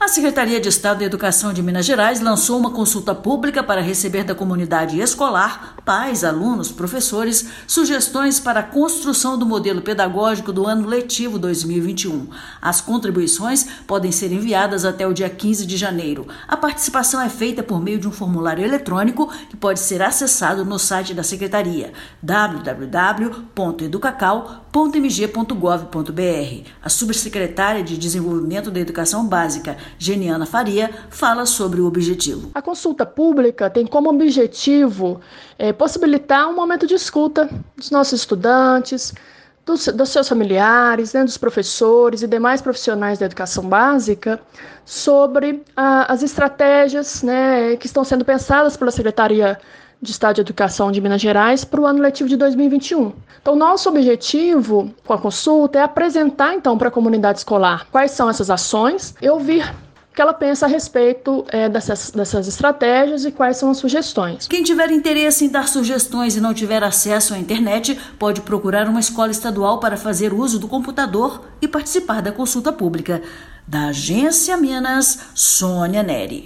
A Secretaria de Estado de Educação de Minas Gerais (SEE/MG) recebe, até 15 de janeiro, contribuições e manifestações em uma consulta pública sobre a construção do modelo pedagógico do ano letivo 2021. Ouça a matéria de rádio.